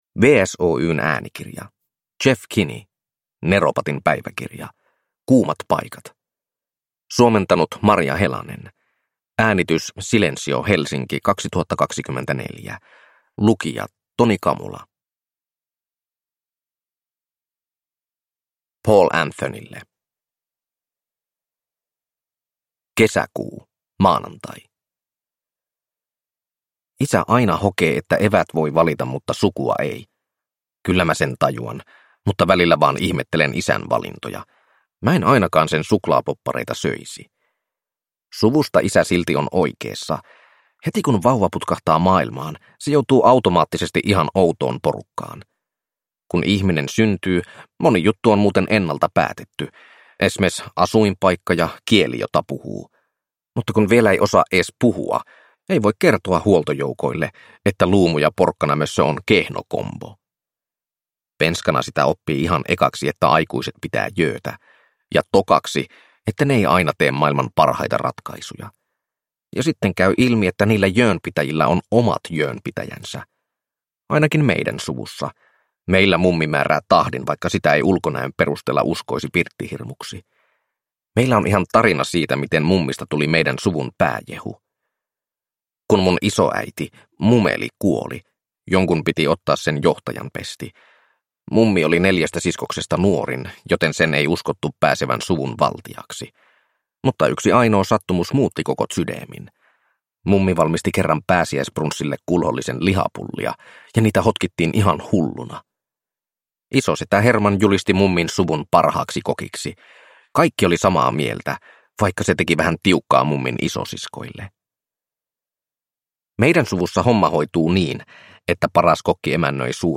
Neropatin päiväkirja: Kuumat paikat – Ljudbok